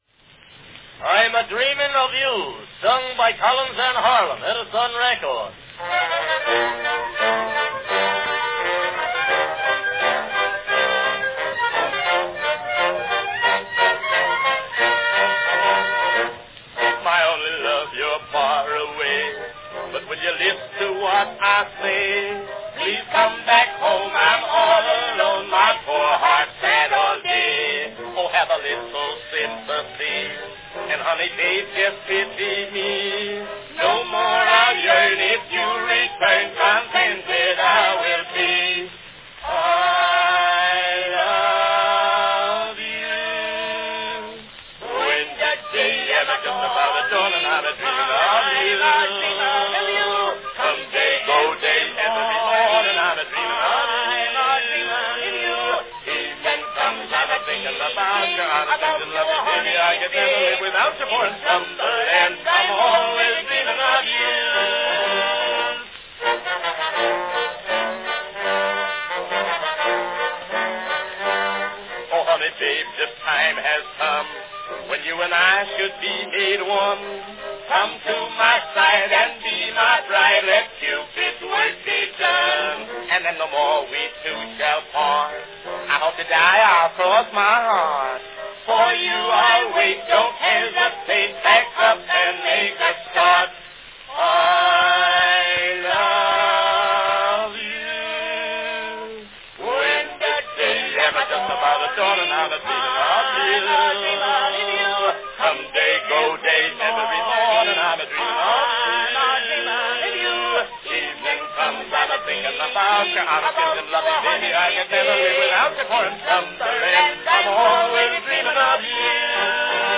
An entertaining ragtime duet from 1906, I'm a-Dreaming of You, sung by Arthur Collins & Byron G. Harlan.
Company Edison's National Phonograph Company
Category Duet
Performed by Arthur Collins & Byron G. Harlan
Another fine recording by Collins & Harlan – unusual for its counterpointing ragtime melodies.
The song is particularly new, showing the two voices in separate melodies and different words.